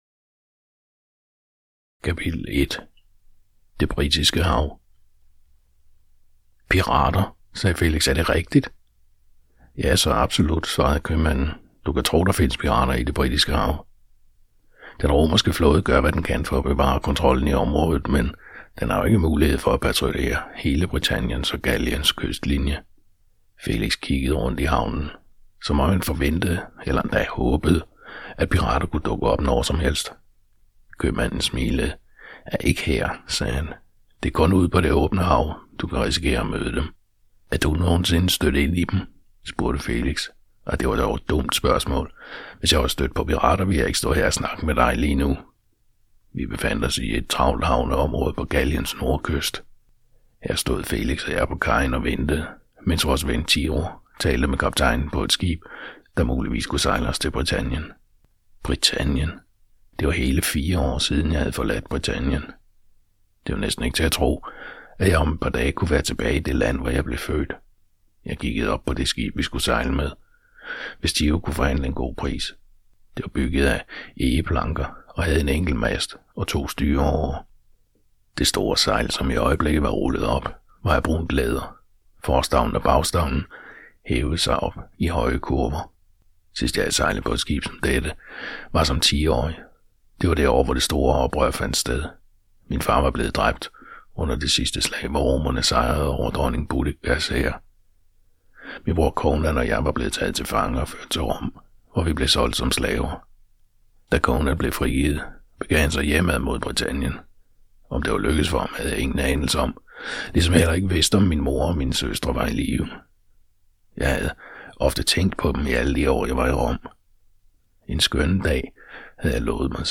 Hør et uddrag af Ved imperiets grænse Ved imperiets grænse Format MP3 Forfatter Kathy Lee Bog Lydbog 99,95 kr.